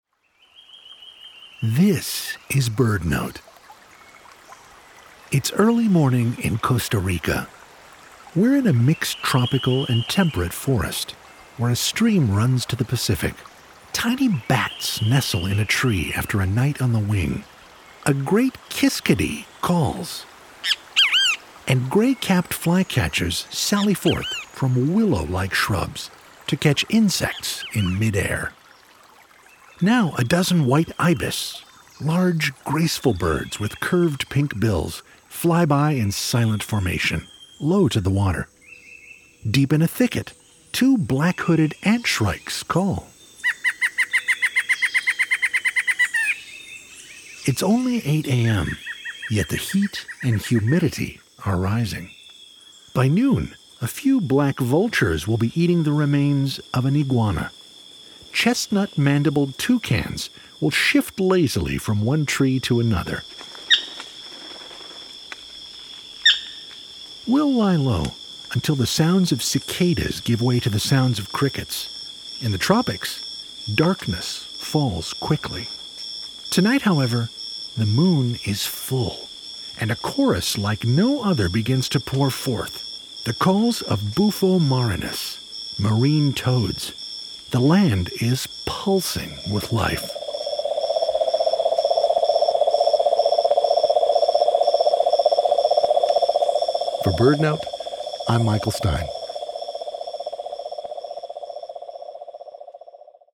A Great Kiskadee calls.
Deep in a thicket, Black-hooded Antshrikes call.
In the evening, the sounds of cicadas give way to the sounds of crickets, and a chorus of Marine Todads pours forth like no other.